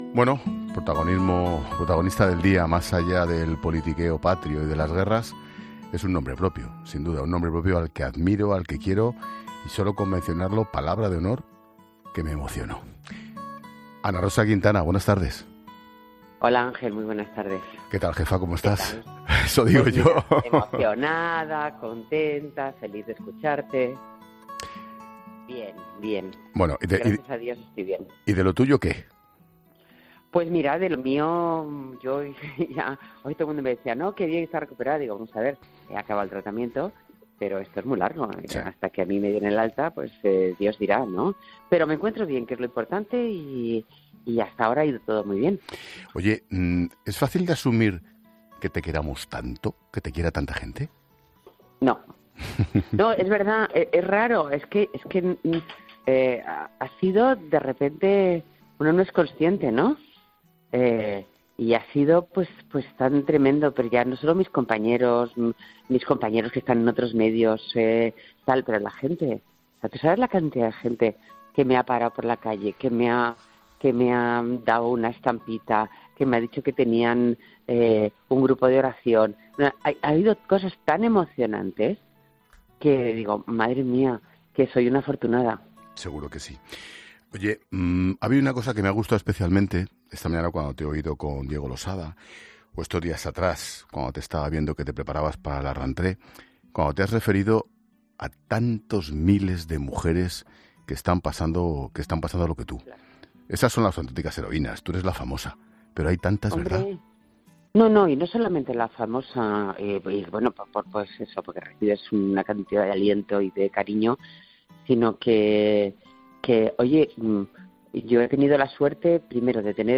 La presentadora ha pasado por los micrófonos de 'La Linterna', donde ha recordado el detalle que tuvo una mujer que le paró por la calle